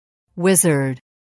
단어번호.0690 대단원 : 3 소단원 : a Chapter : 03a 직업과 사회(Work and Society)-Professions(직업) wizard [wízərd] 명) (남자) 마법사 mp3 파일 다운로드 (플레이어바 오른쪽 아이콘( ) 클릭하세요.)
wizard.mp3